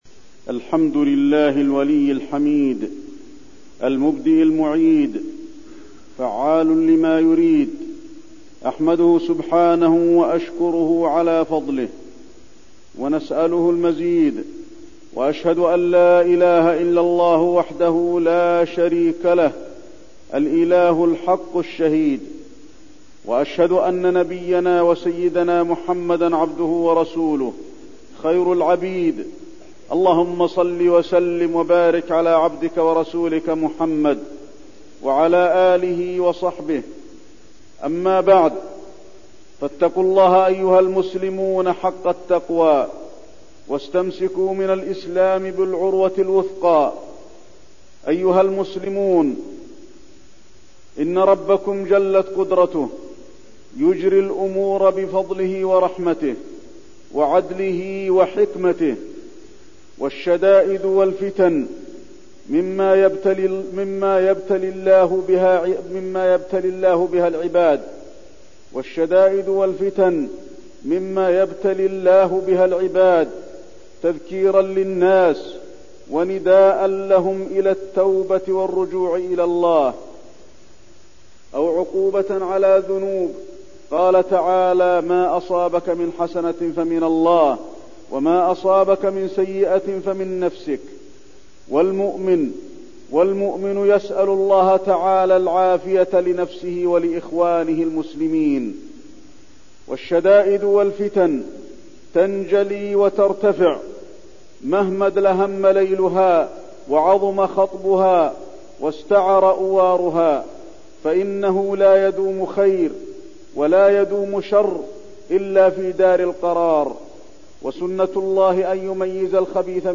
تاريخ النشر ٨ شعبان ١٤١١ هـ المكان: المسجد النبوي الشيخ: فضيلة الشيخ د. علي بن عبدالرحمن الحذيفي فضيلة الشيخ د. علي بن عبدالرحمن الحذيفي ما يعصم من الفتن